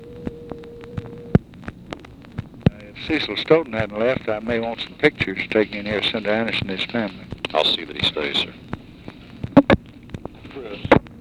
Conversation with GEORGE REEDY, April 20, 1964
Secret White House Tapes